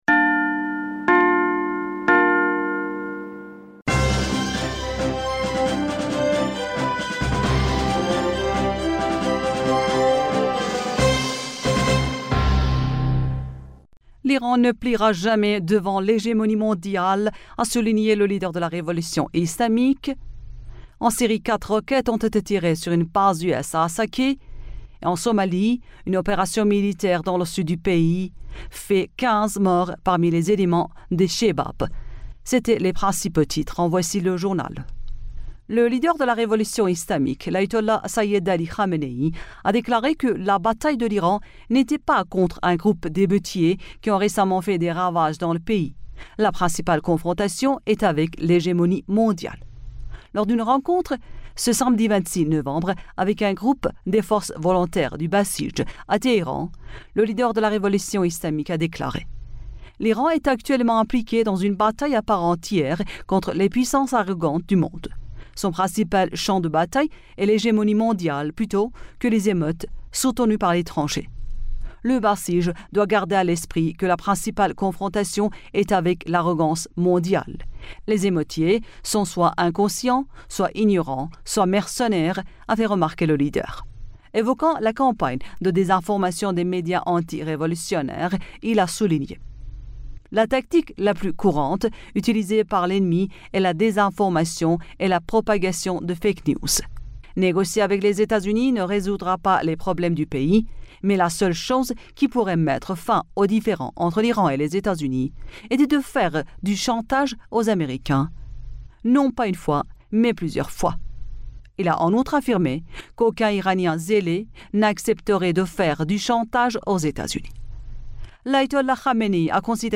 Bulletin d'information du 26 Novembre